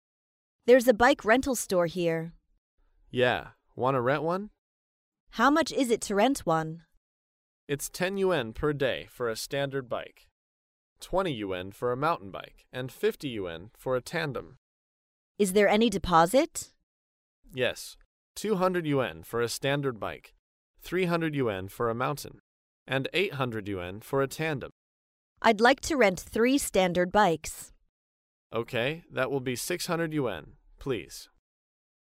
在线英语听力室高频英语口语对话 第394期:租自行车(2)的听力文件下载,《高频英语口语对话》栏目包含了日常生活中经常使用的英语情景对话，是学习英语口语，能够帮助英语爱好者在听英语对话的过程中，积累英语口语习语知识，提高英语听说水平，并通过栏目中的中英文字幕和音频MP3文件，提高英语语感。